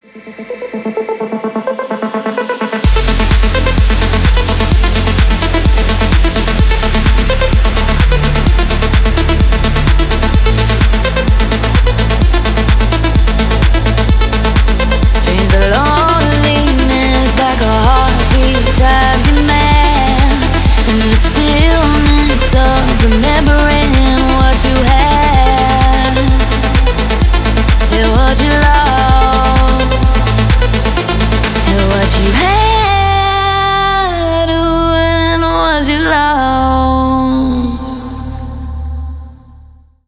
Электроника
клубные